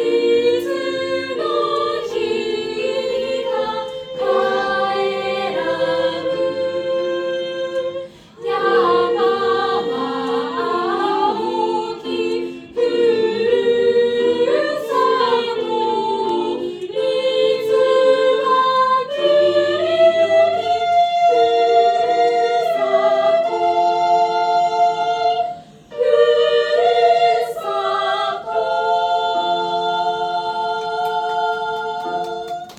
ナーシング・ヴィラ2号館クリスマス会2025
ふるさと-合唱部.mp3